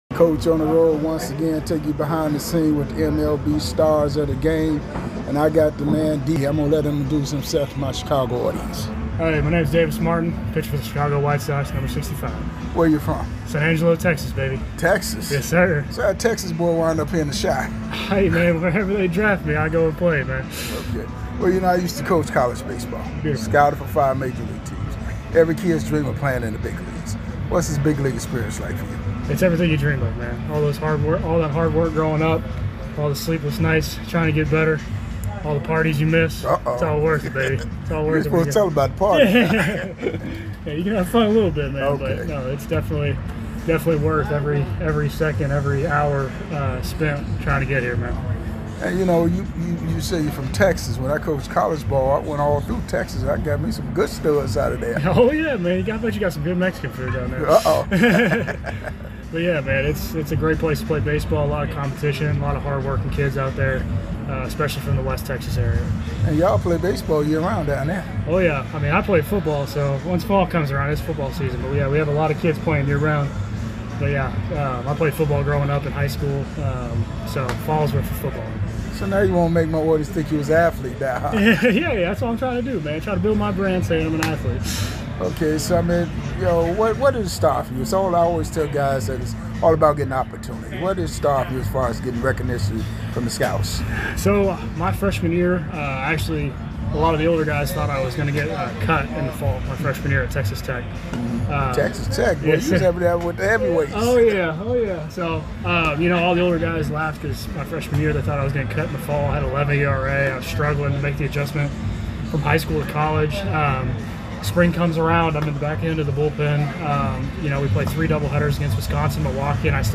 ⚾ MLB Classic Interviews